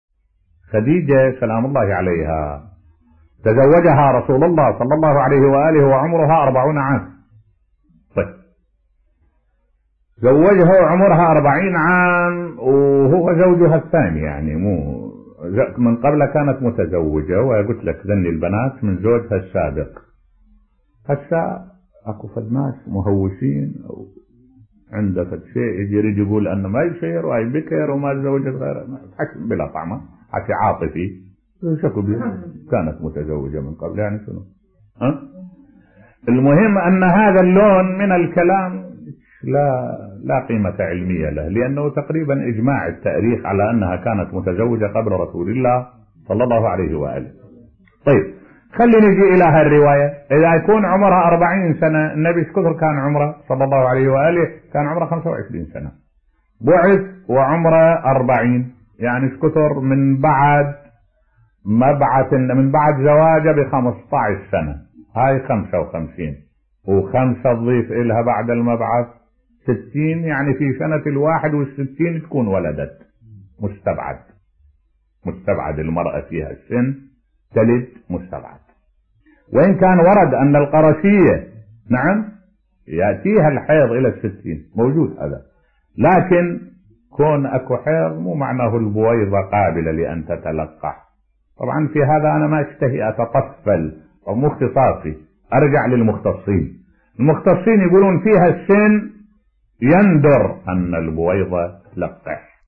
ملف صوتی ان السيدة خديجة (ع) كانت متزوجة قبل الرسول الأعظم (ص) بصوت الشيخ الدكتور أحمد الوائلي